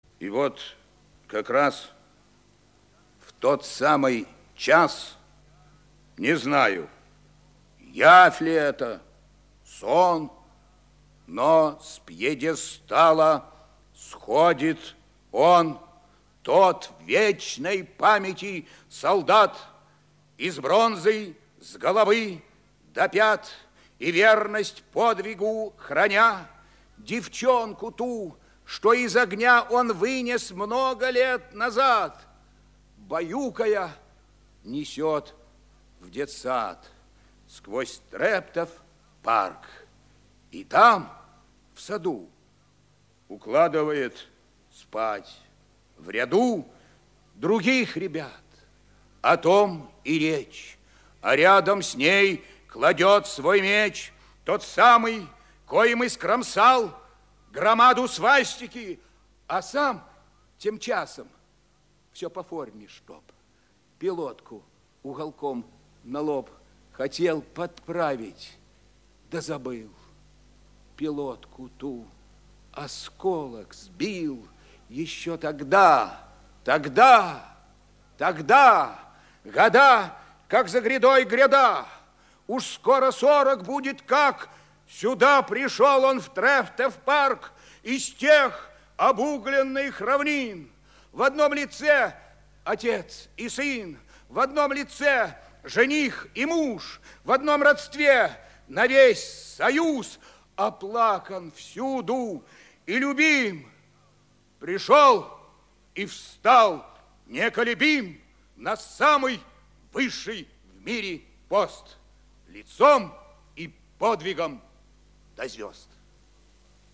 1. «Егор Исаев – Отрывок из поэмы Двадцать пятый час (читает автор)» /
Isaev-Otryvok-iz-poemy-Dvadcat-pyatyy-chas-chitaet-avtor-stih-club-ru.mp3